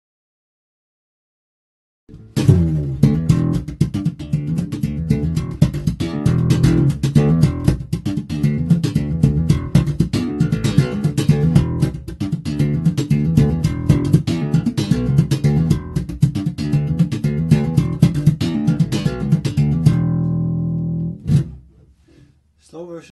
in E minor